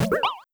Water9.wav